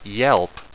Prononciation du mot yelp en anglais (fichier audio)
Prononciation du mot : yelp